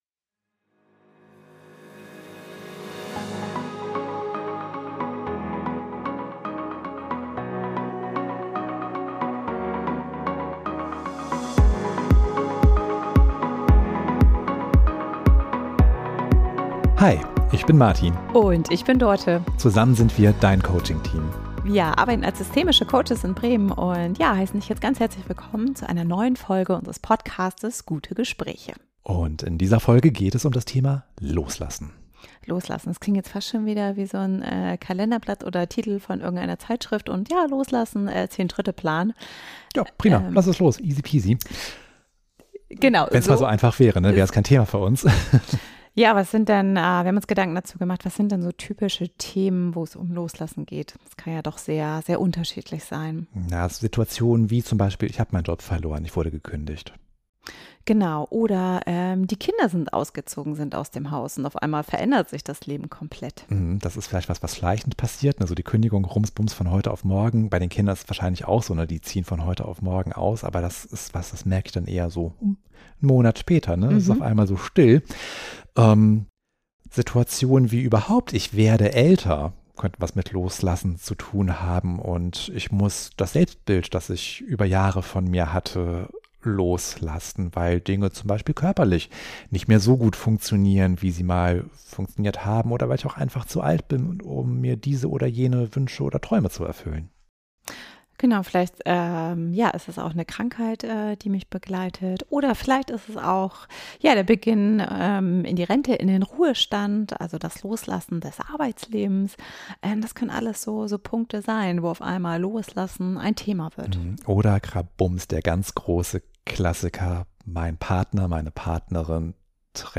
ein gutes Gespräch übers Abschied nehmen, über Schmerz, Trauer und Zukunftsängste.